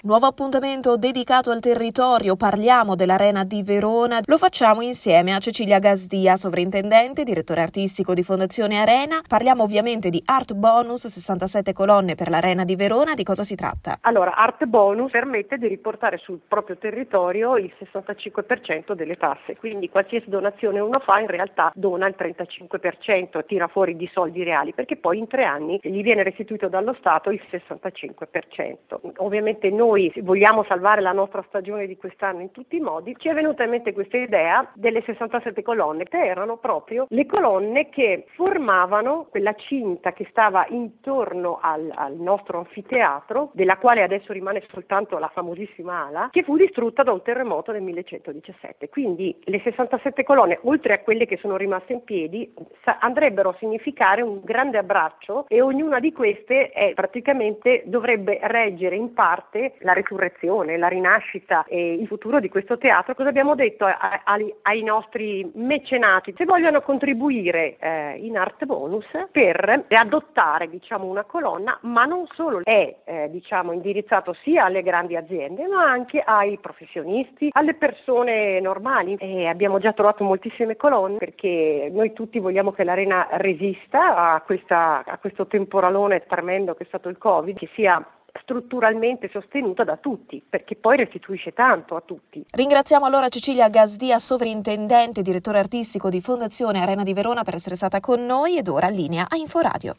07 Aprile 2021: Intervista a Cecilia Gasdia Sovrintendente Direttore Artistico di Fondazione Arena a proposito di “67 Colonne per l’Arena di Verona”: